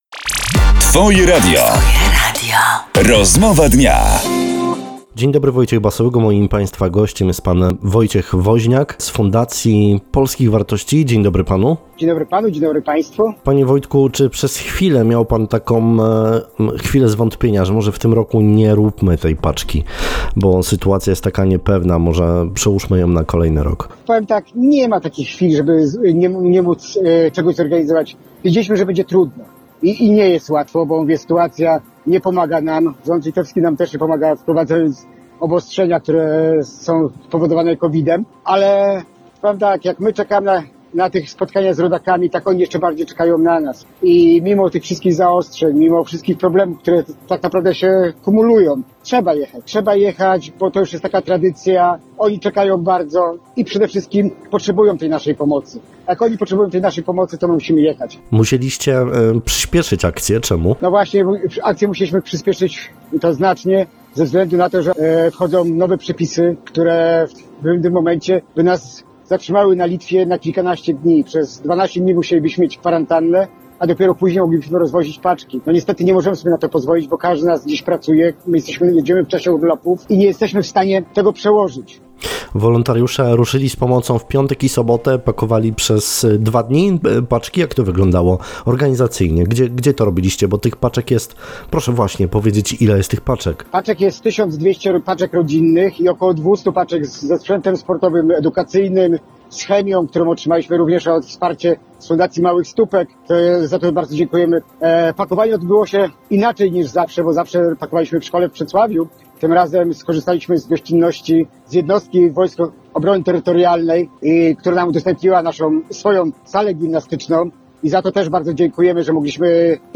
Mówił o tym dziś na naszej antenie